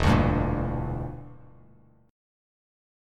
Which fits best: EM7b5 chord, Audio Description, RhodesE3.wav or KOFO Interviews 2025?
EM7b5 chord